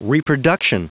Prononciation du mot reproduction en anglais (fichier audio)
Prononciation du mot : reproduction